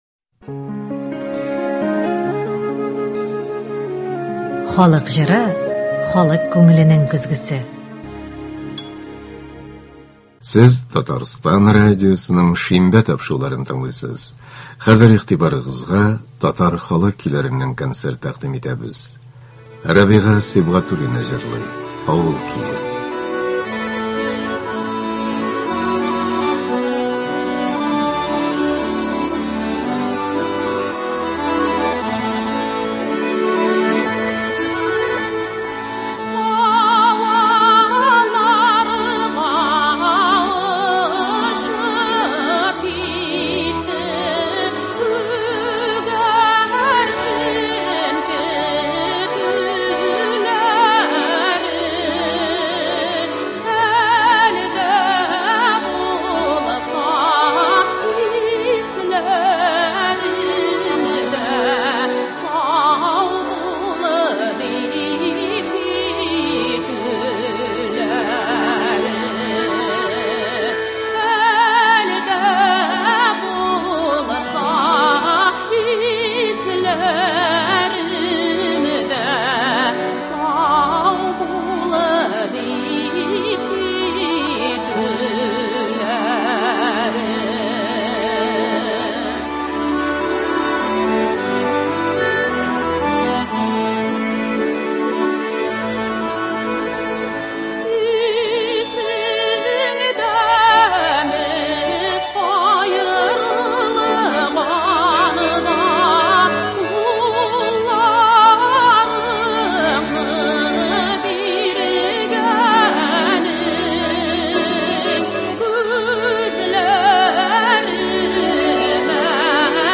Татар халык көйләре (20.01.24)